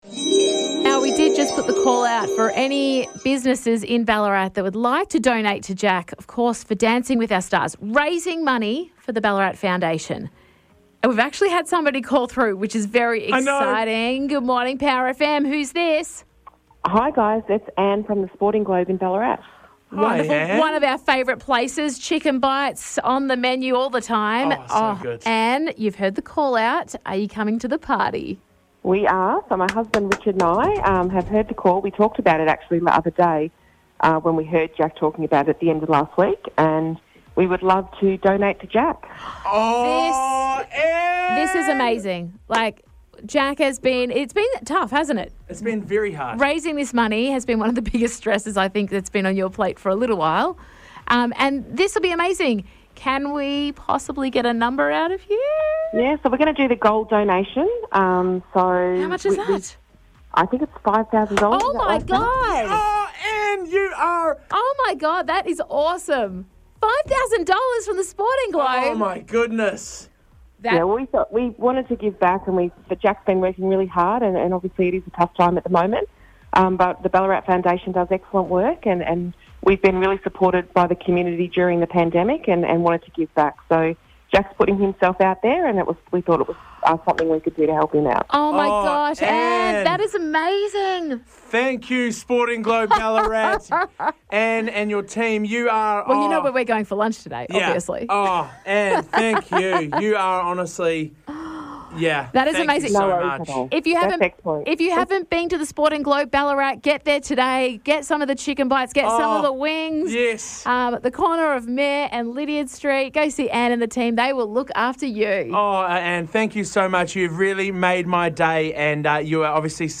called the guys in the studio today